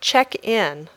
Haz clic en la palabra para escuchar su pronunciación y repetirla.